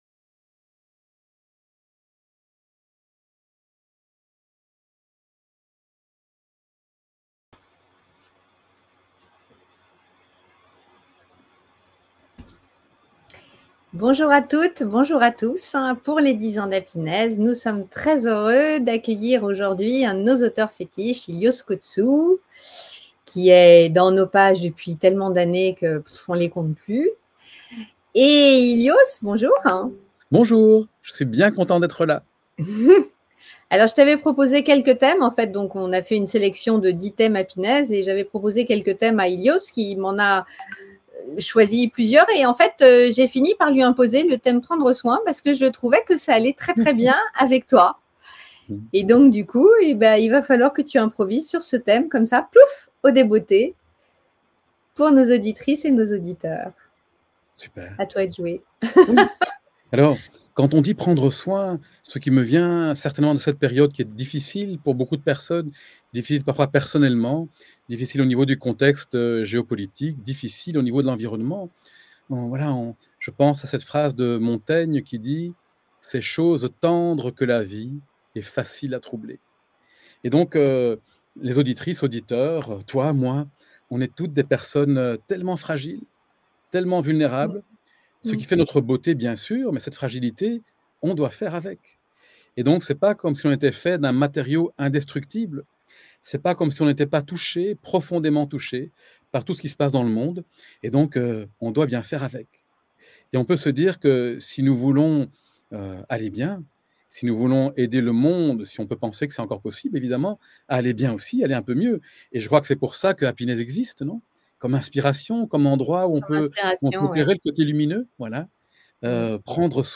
Interview 10 ans - Prendre soin